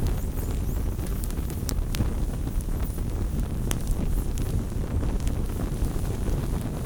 sfx_fire_burning.wav